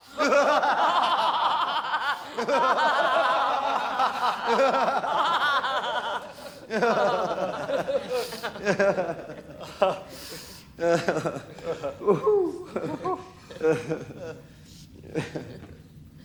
Звуки со смехом злого ученого, маньяка и его лаборатория для монтажа видео в mp3 формате.
3. Смех толпы ученых